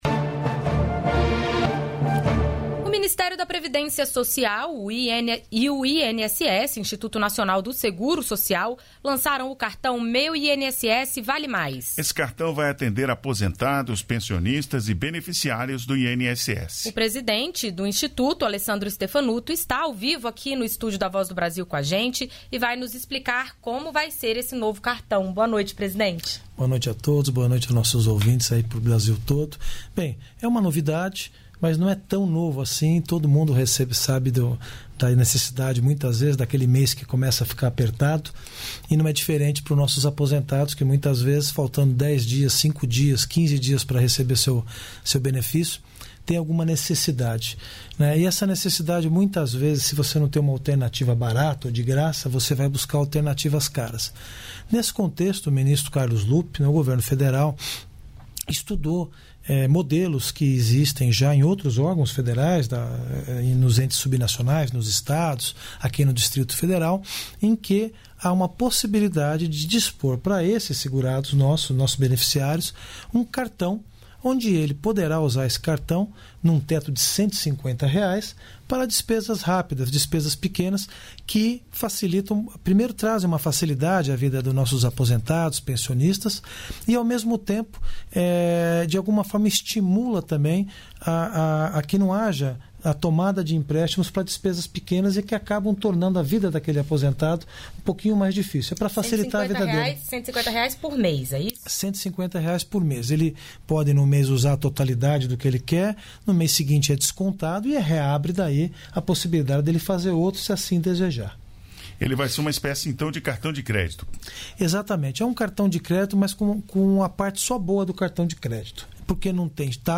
Entrevistas da Voz Presidente da Companhia Nacional de Abastecimento (Conab), Edegar Pretto.